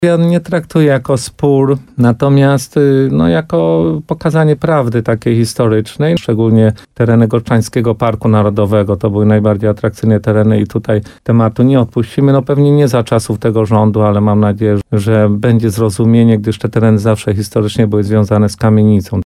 Jak powiedział wójt Kamienicy Władysław Sadowski, nie chodzi o konfliktowanie się z kimkolwiek, ale o sprawiedliwość.